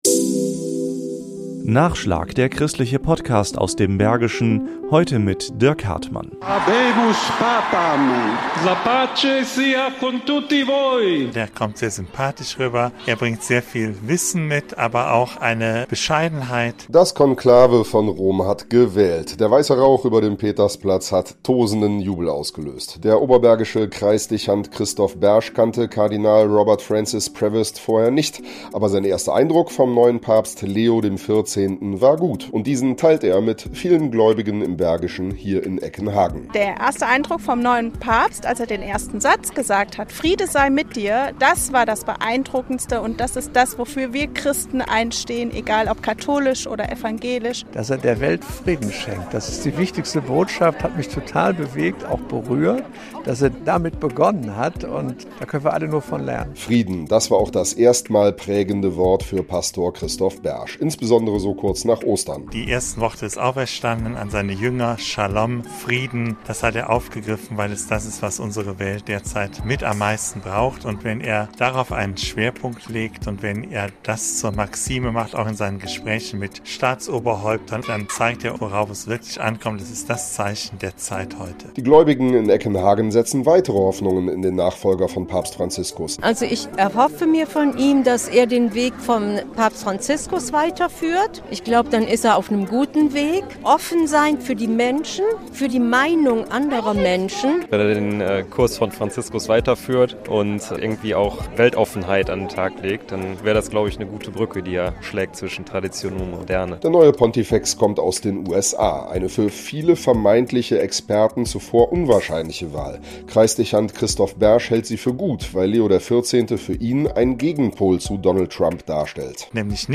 Stimmen aus dem Oberbergischen Kreis